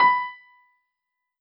piano-ff-63.wav